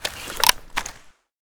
ak74_draw.ogg